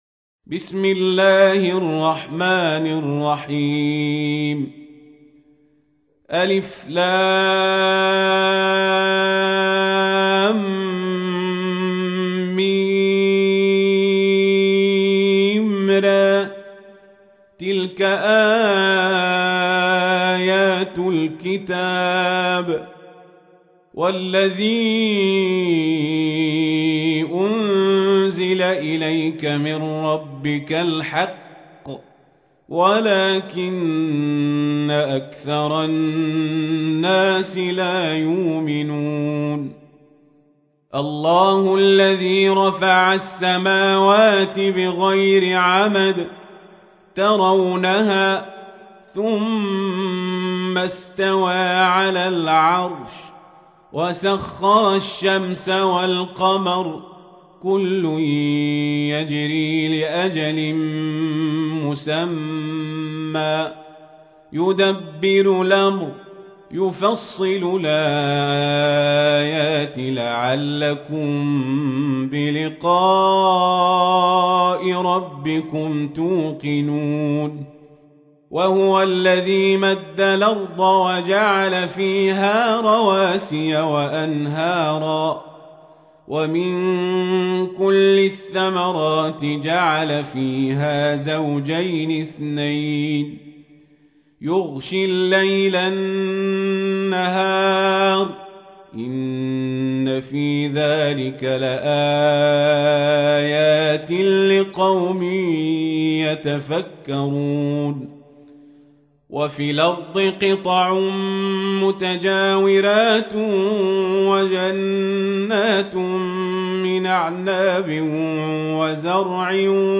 تحميل : 13. سورة الرعد / القارئ القزابري / القرآن الكريم / موقع يا حسين